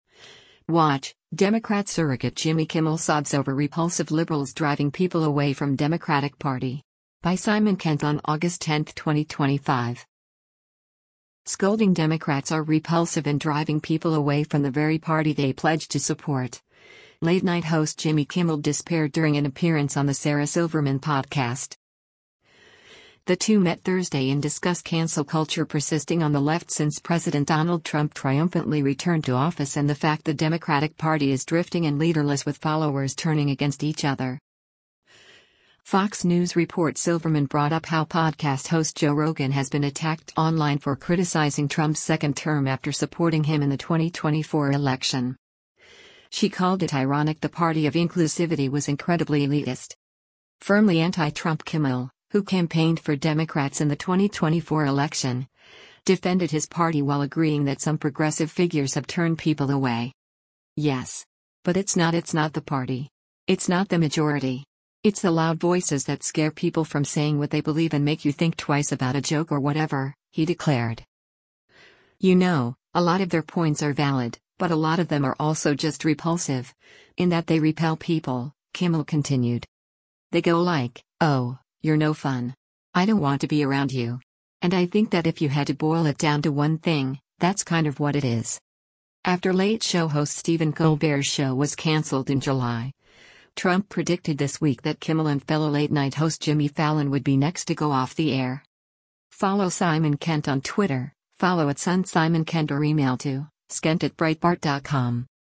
Scolding Democrats are “repulsive” and driving people away from the very party they pledge to support, late-night host Jimmy Kimmel despaired during an appearance on the Sarah Silverman Podcast.